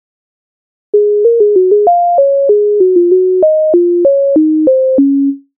MIDI файл завантажено в тональності Cis-dur